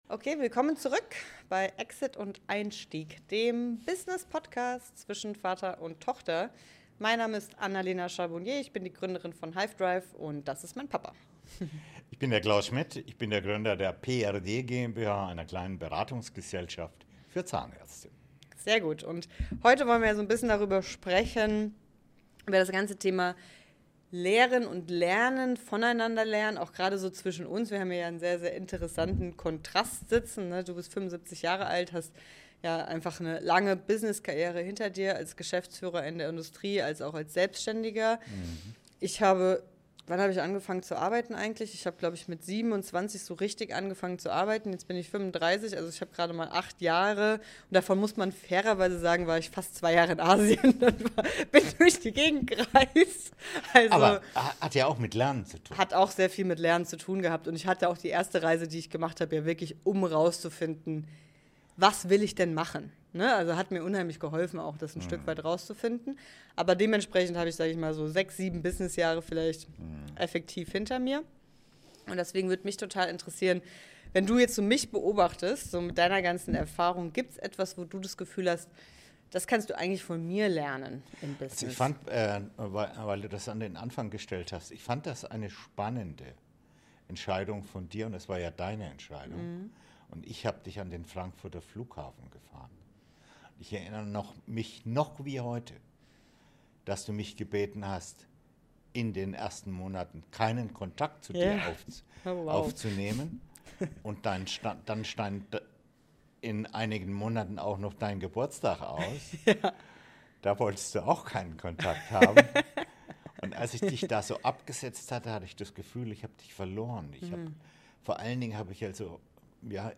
Ein Gespräch über Freiheit als inneren Zustand, über Angst versus Intuition und darüber, warum gesunde Risiken oft der Beginn von Wachstum sind.